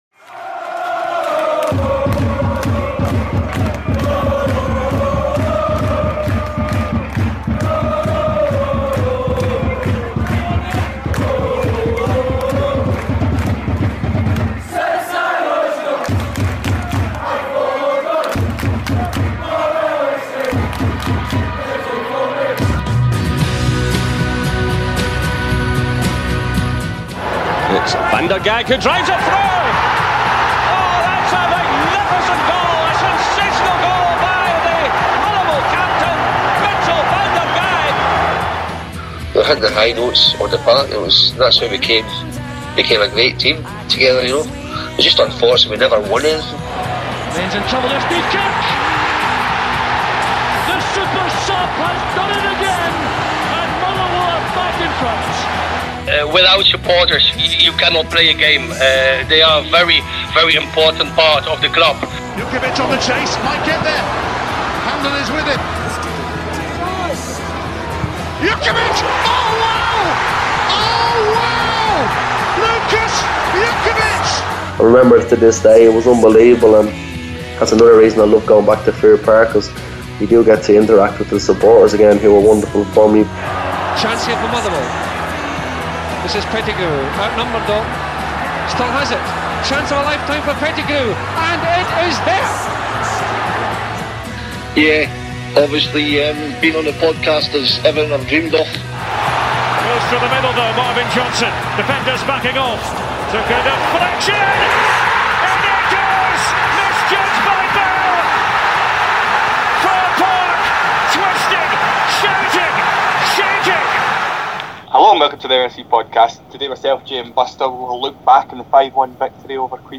Please note: due to some technical difficulties, we’ve had to upload a makeshift backup recording of this week’s podcast – apologies for any below par audio!